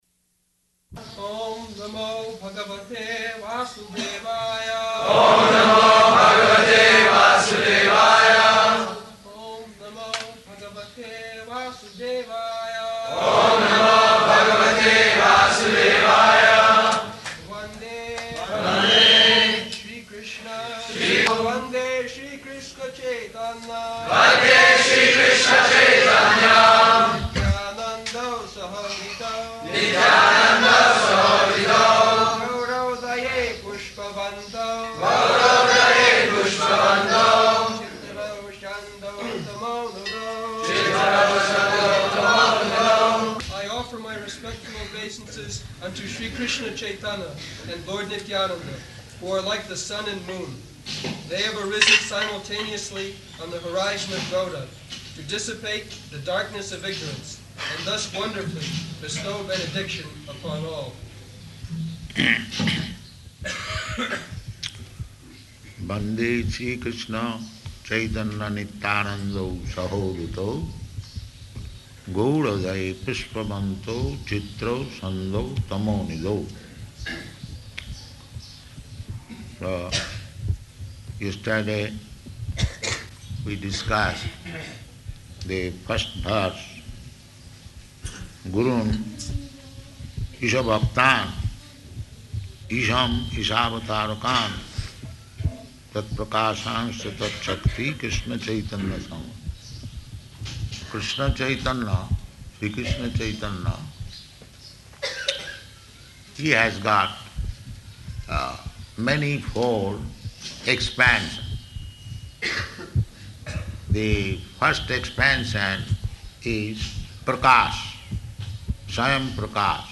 March 26th 1975 Location: Māyāpur Audio file